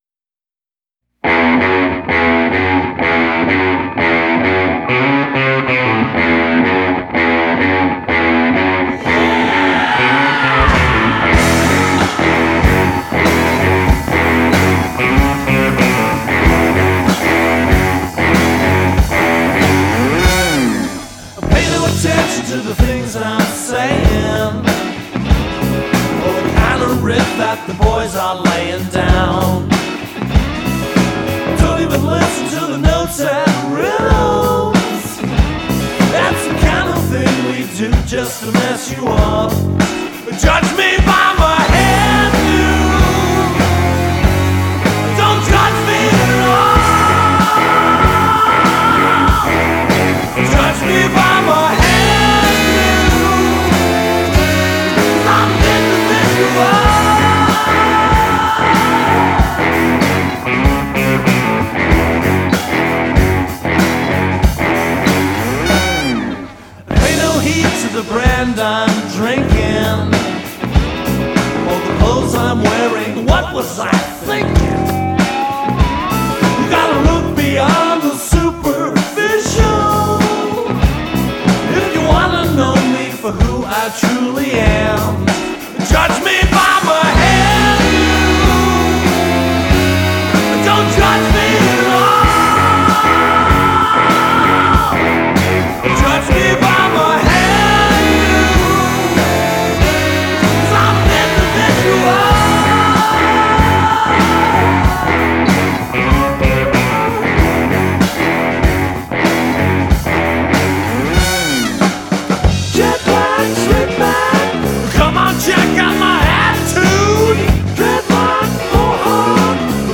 I keep hearing some slight phasing in snare drum....
funky and addictive.... Vocals are outstanding.....
The mix is superb.
Harp break is perfect.....
The only thing that I didn't care for was the tink tink sound on the end of the keyboard/organ hits.
THe "tink" sound is probably the percussion on the organ patch I'm using.